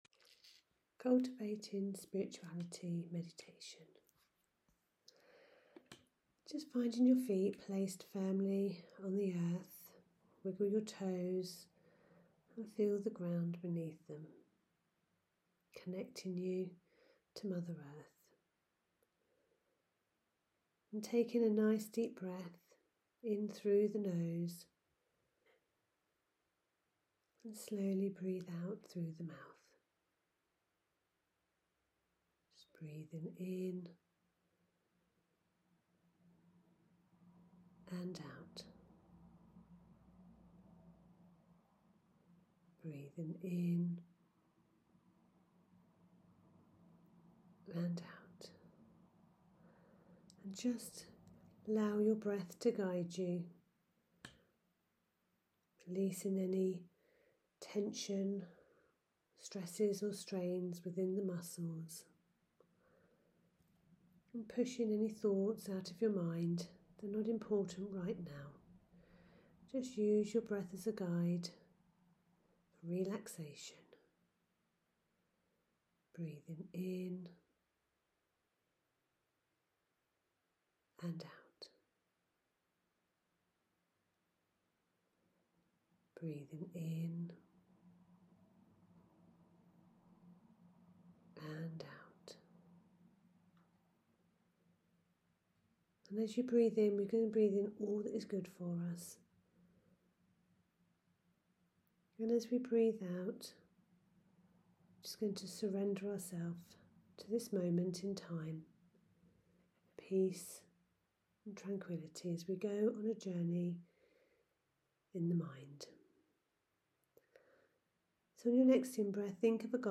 (Meditation link below)